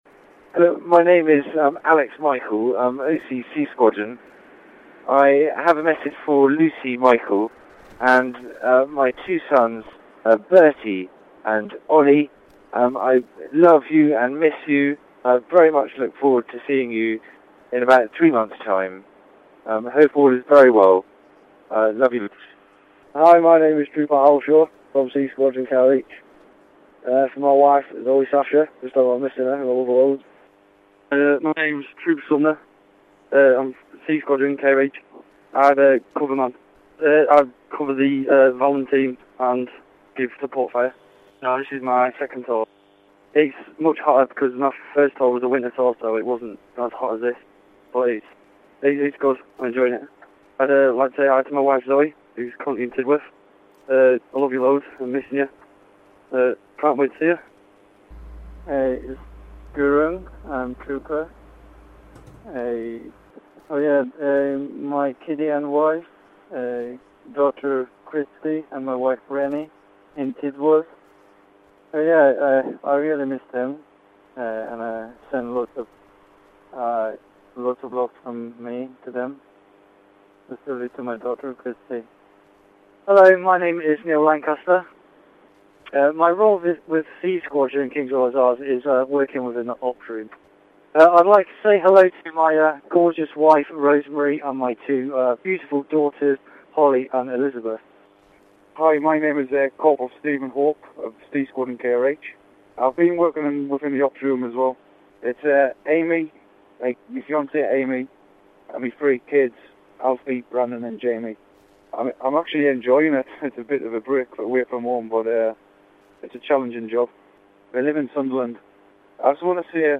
Soldiers from C Squadron send their messages to family back home